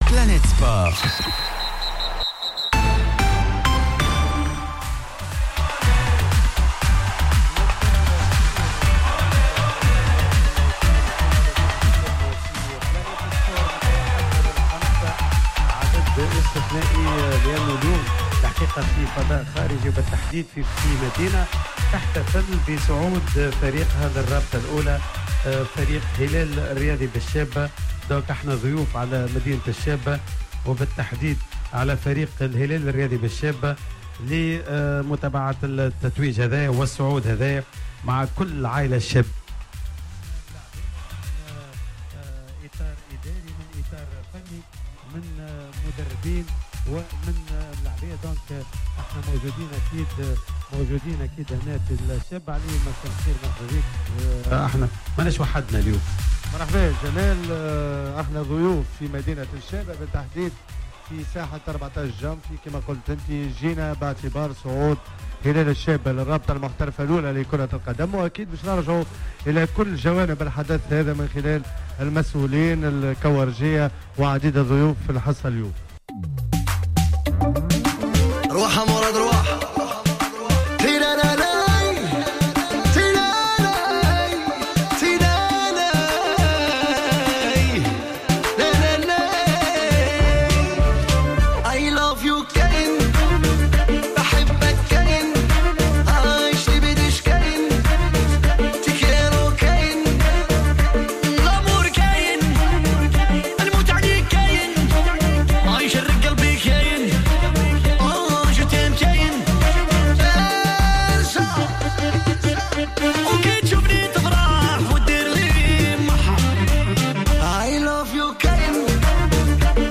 وتم بث الحصة من إستوديو خارجي لجوهرة أف أم مباشرة من مدينة الشابة بحضور عدد من مسؤولي و لاعبي و أحباء الفريق.